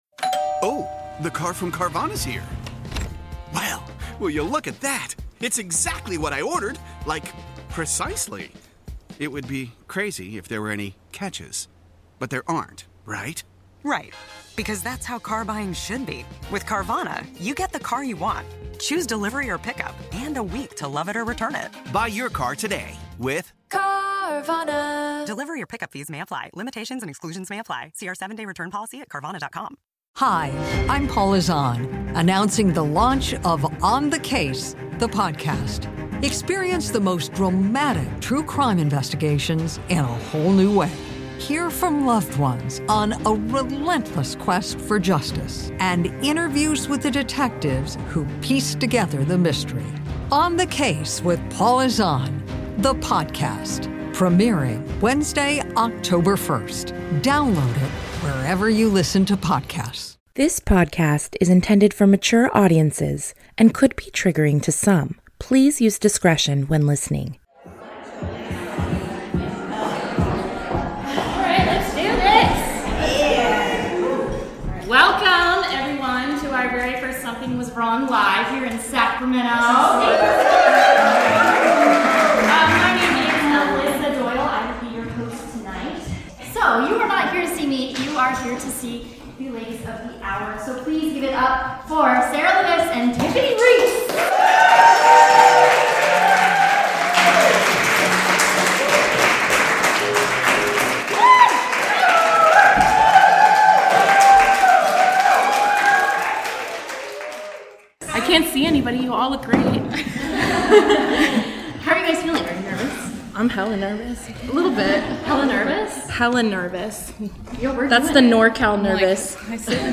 SWW Live took place Saturday August 24th 2019 at The Sophia at B Street Theater in Midtown Sacramento.